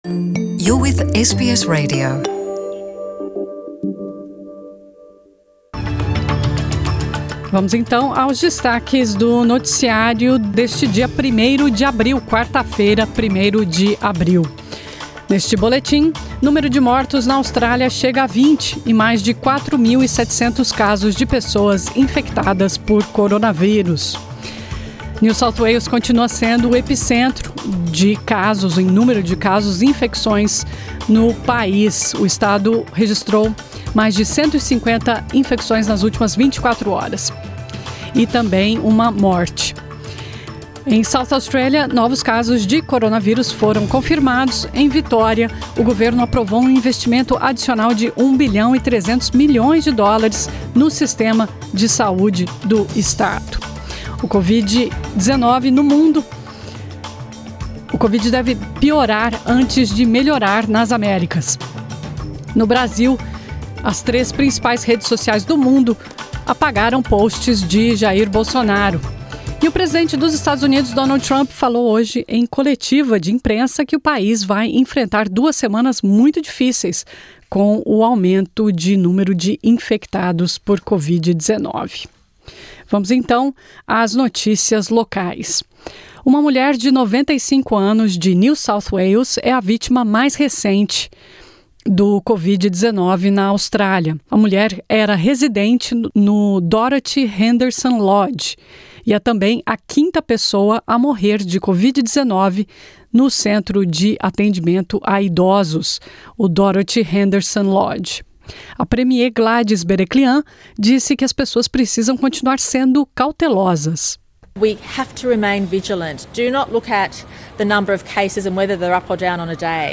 Em New South Wales, uma clínica pop-up de testes para COVID-19 vai abrir na praia de Bondi para ajudar a minimizar a propagação do coronavírus. Ouça esse e outros destaques do noticiário desta quarta-feira 1º de abril.